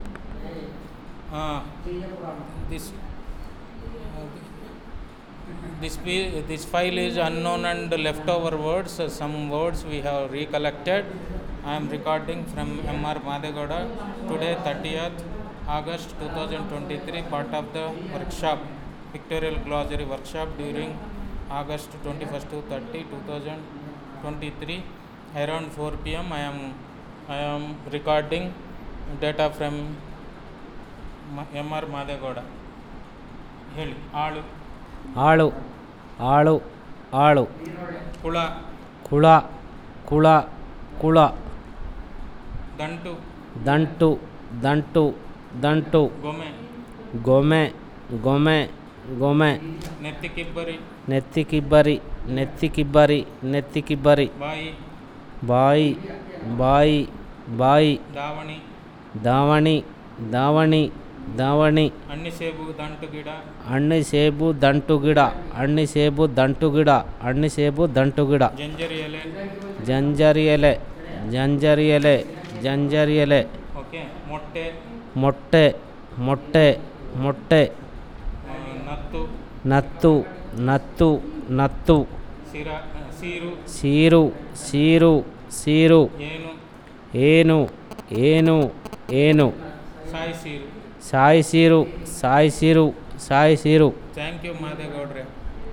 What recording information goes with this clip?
NotesThis is an elicitation of wordlist on Multidomain related while using pictures in pictorial glossary.